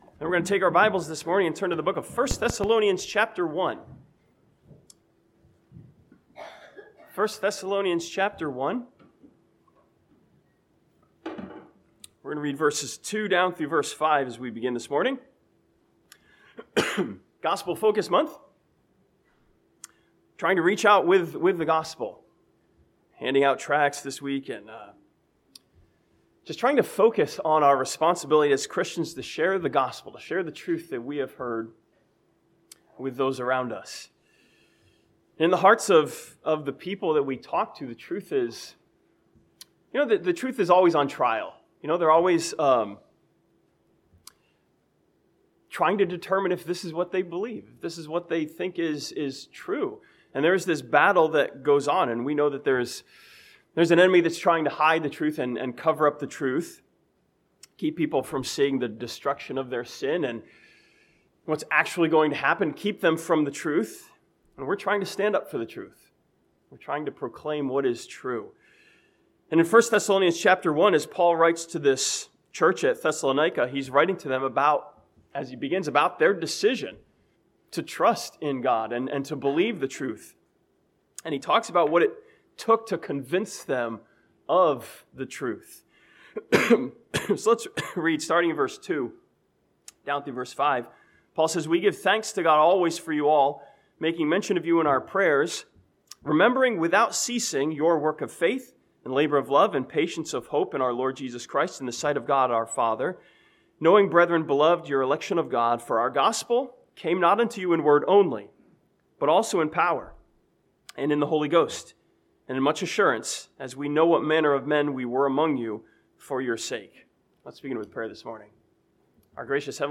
This sermon from 1 Thessalonians chapter 1 challenges us to make sure both the message and the messenger are right.